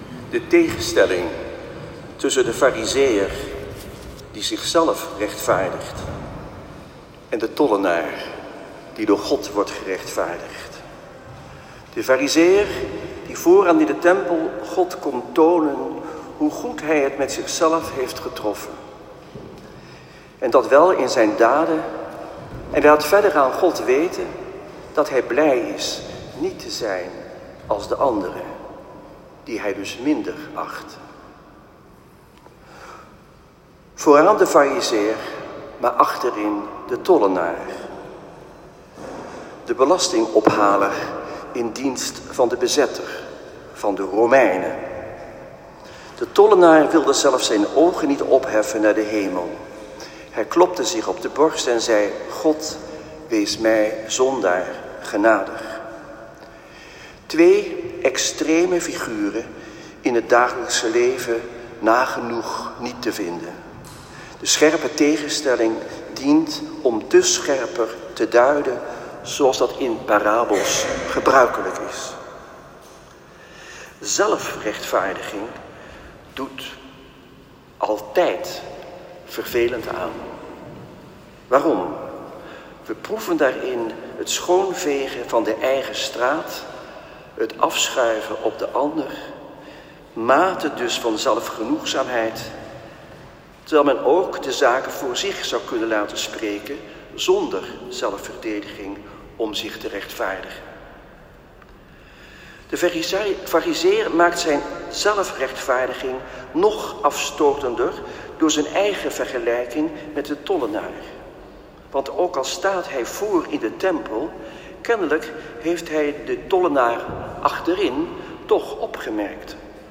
Dertigste zondag door het jaar C. Celebrant Antoine Bodar.
Preek-1.m4a